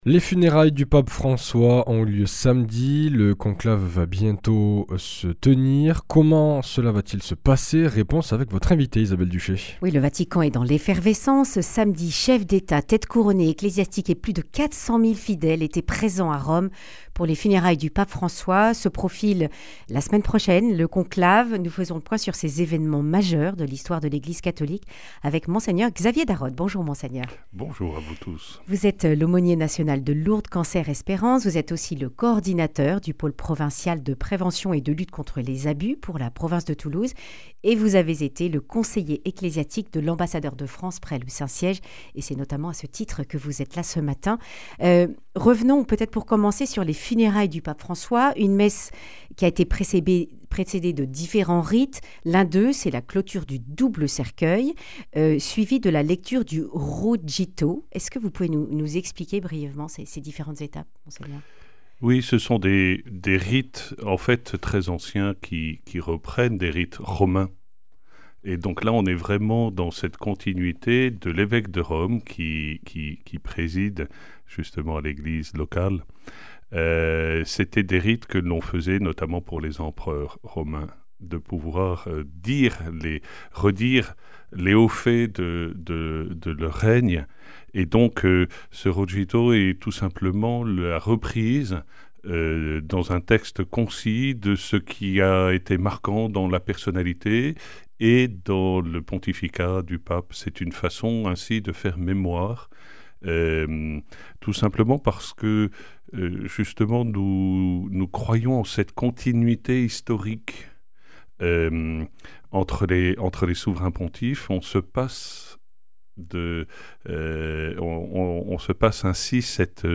Accueil \ Emissions \ Information \ Régionale \ Le grand entretien \ Funérailles du pape François, congrégations générales, quels en sont les rites (...)